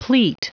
Prononciation du mot pleat en anglais (fichier audio)
Prononciation du mot : pleat